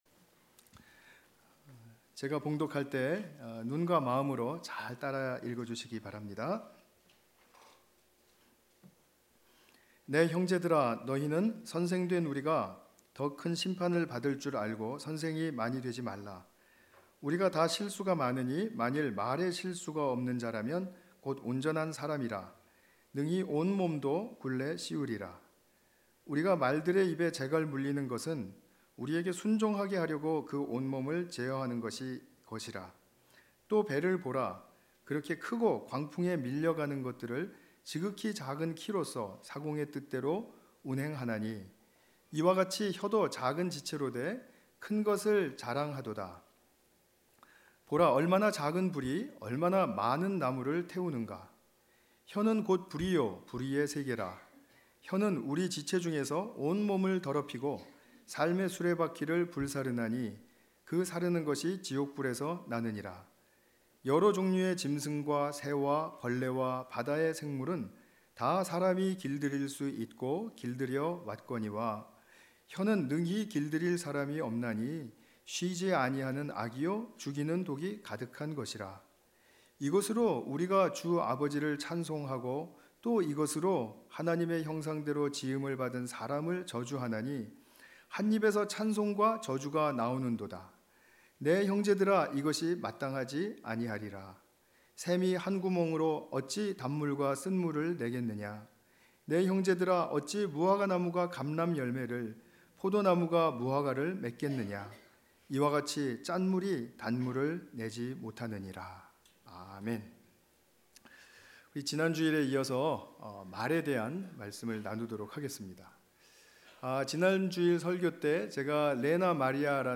관련 Tagged with 주일예배 Audio (MP3) 68 MB 이전 야고보서 (11) - 말로 의롭다 함을 받으라 다음 하나님과 함께 걷는 인생 여행 0 댓글 댓글 추가 취소 댓글을 달기 위해서는 로그인 해야합니다.